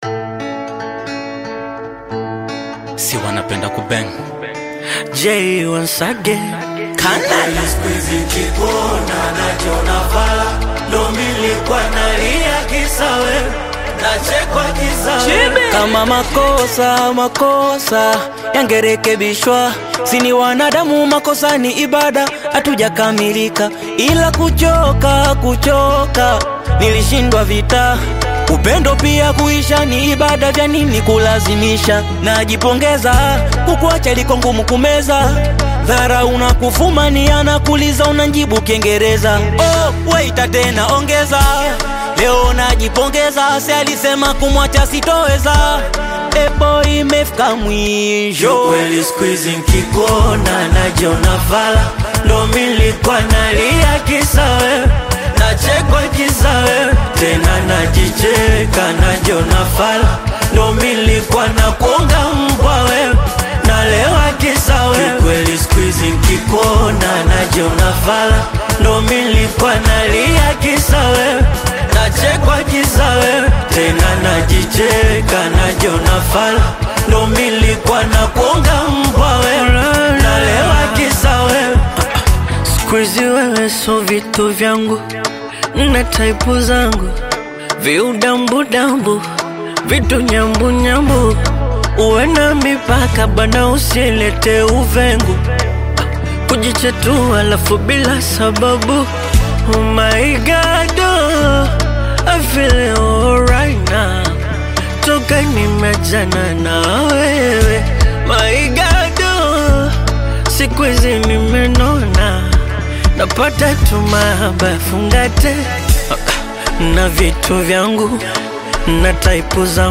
infectious Afro-Beat/Bongo Flava anthem
smooth vocals
Genre: Amapiano